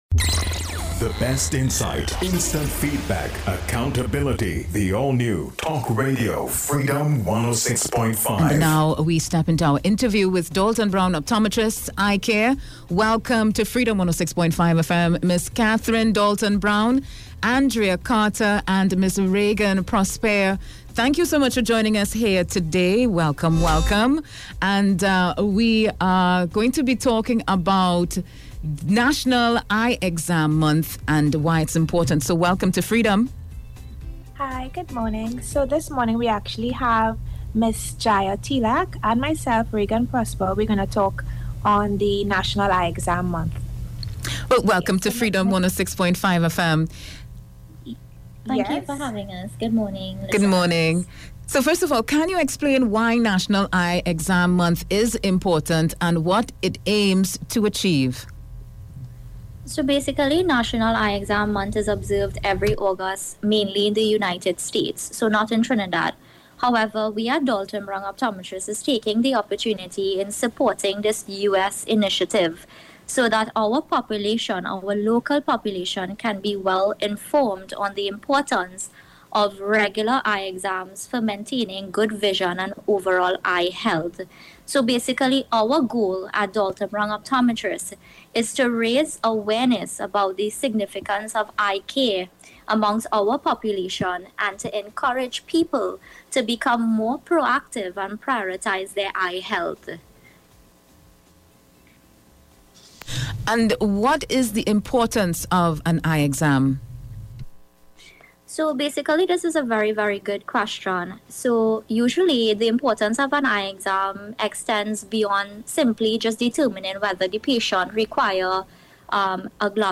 DALTON BROWN OPTOMETRISTS INTERVIEW